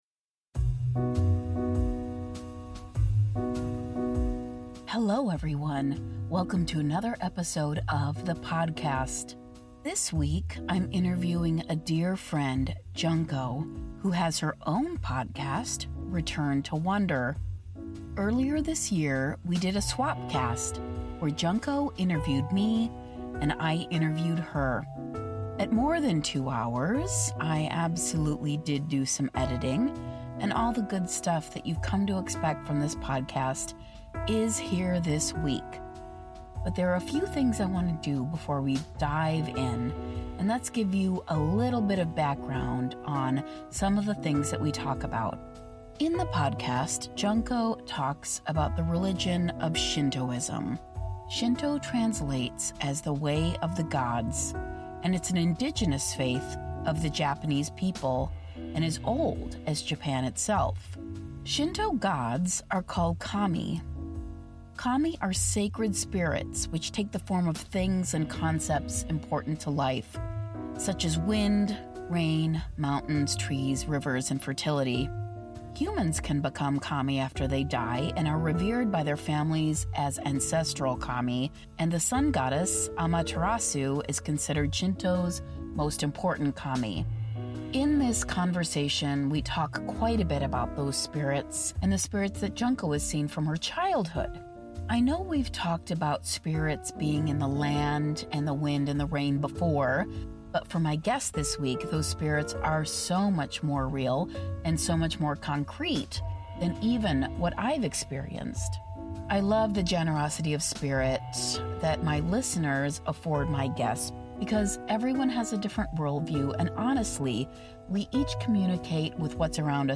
The Interview